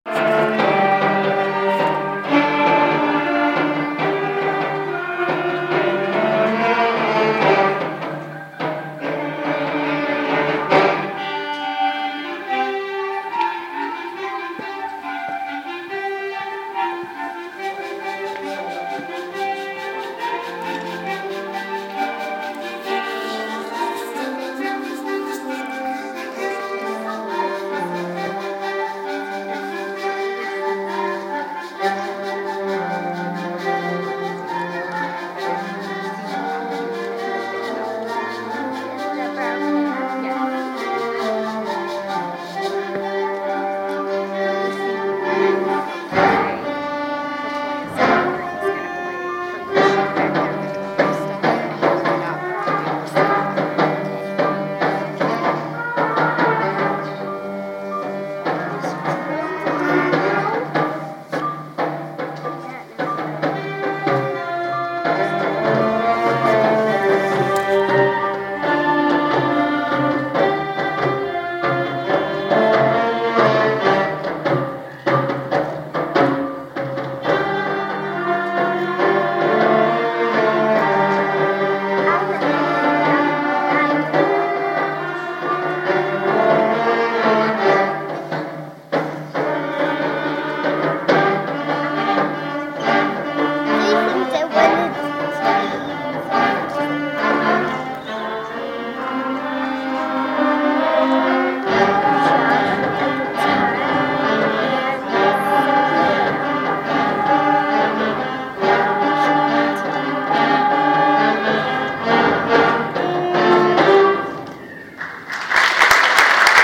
6th grade band sampler...